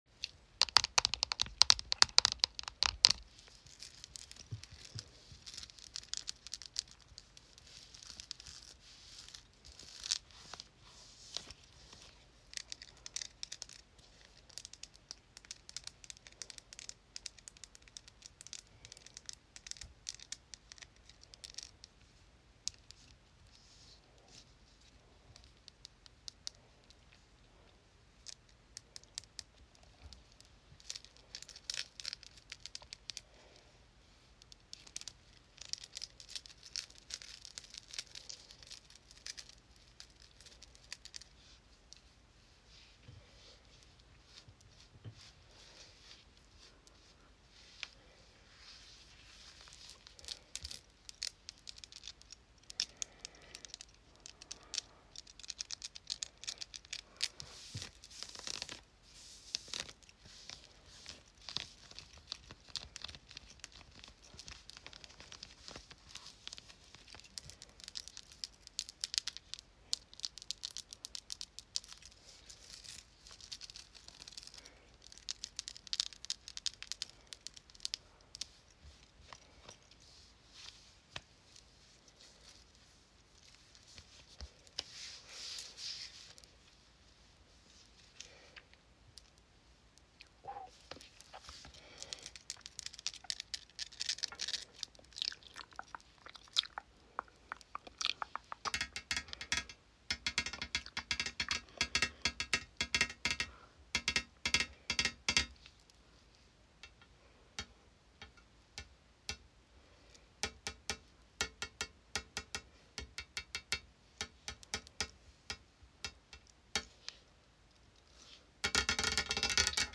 Jewelry tapping body scratching NO